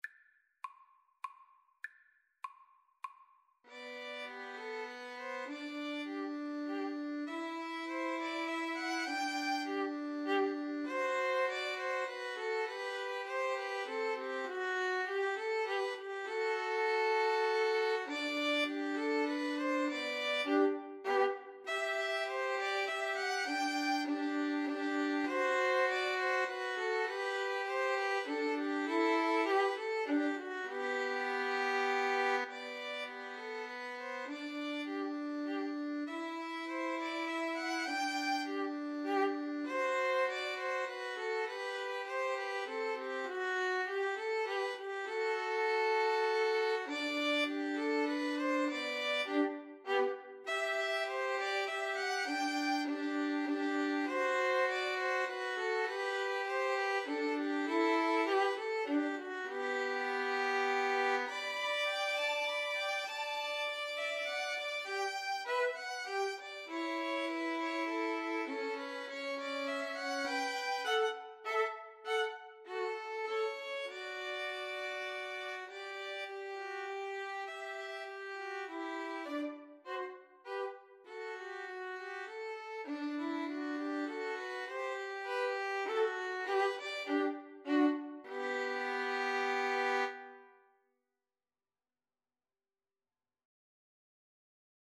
Free Sheet music for Violin Trio
G major (Sounding Pitch) (View more G major Music for Violin Trio )
3/4 (View more 3/4 Music)
Classical (View more Classical Violin Trio Music)